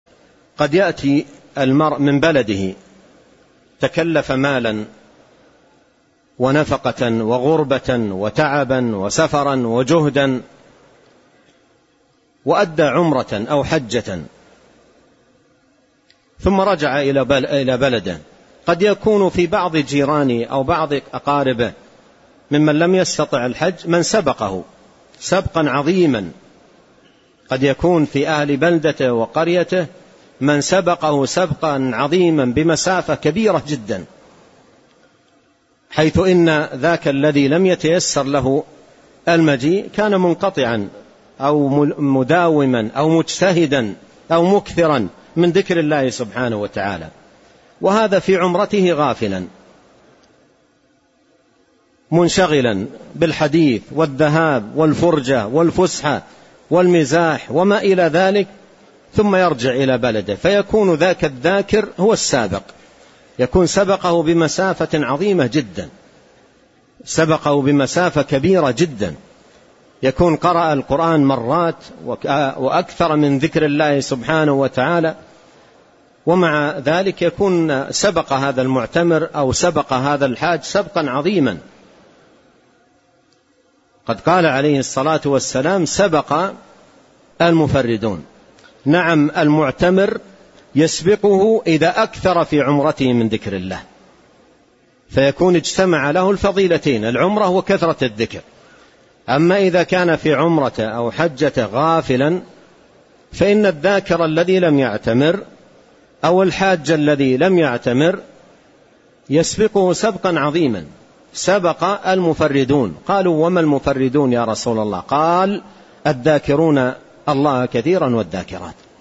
📚شرح الوابل الصيب | الدرس (15).